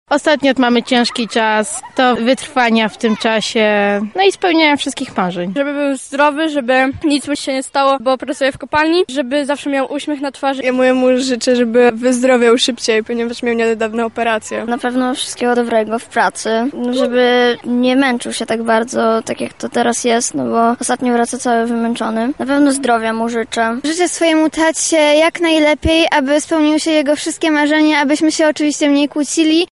Czego lublinianie życzą swoim ojcom? [SONDA]
sonda